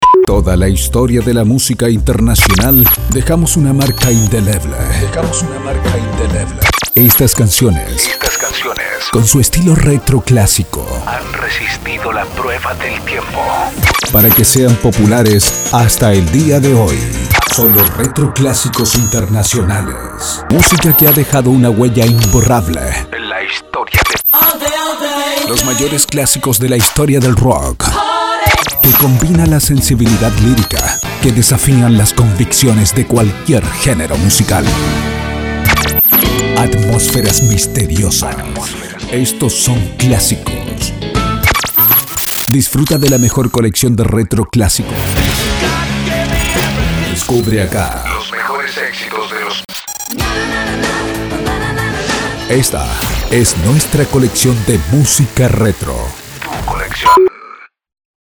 Male
Adult (30-50)
Locutor versátil, desde lo formal a lo informal, pasando por personales hasta locuciones sofisticadas, solo bastará un buen guion/ reff para comprender su proyecto y darle forma, principalmente soy una voz grave.
Studio Quality Sample
Continuidad Radio